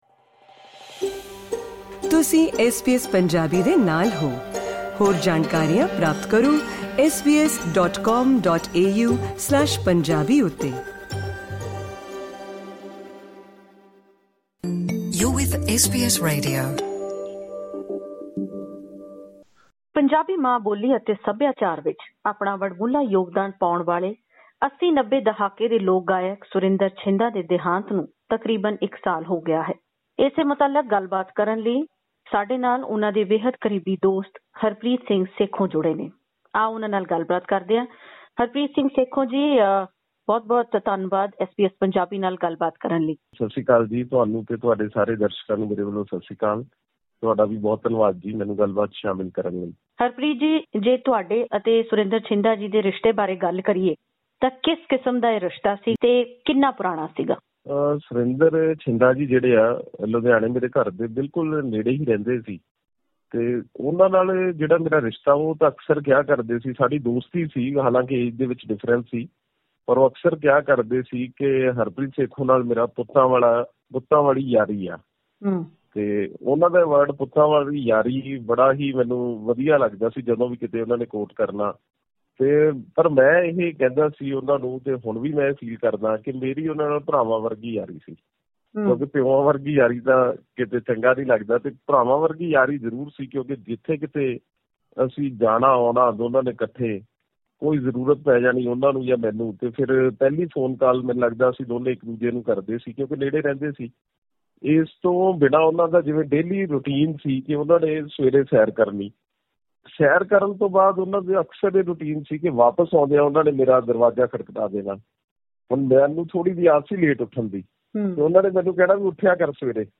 ਉਨ੍ਹਾਂ ਨੇ ਸੁਰਿੰਦਰ ਛਿੰਦਾ ਨੂੰ ਨਾ ਸਿਰਫ ਇੱਕ ਗਾਇਕ,ਕਲਾਕਾਰ ਬਲਕਿ ਇੱਕ ਹਸਮੁੱਖ ਇਨਸਾਨ ਵਜੋਂ ਦੱਸਦਿਆਂ ਐਸ ਬੀ ਐਸ ਪੰਜਾਬੀ ਨਾਲ ਇਹ ਖਾਸ ਗੱਲਬਾਤ ਕੀਤੀ।